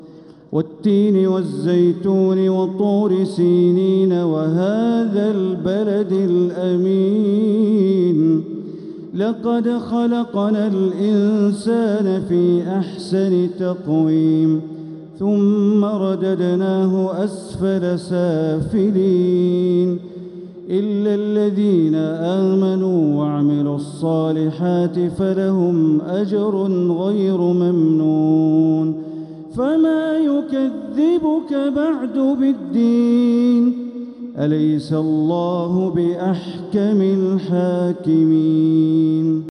سورة التين | مصحف تراويح الحرم المكي عام 1446هـ > مصحف تراويح الحرم المكي عام 1446هـ > المصحف - تلاوات الحرمين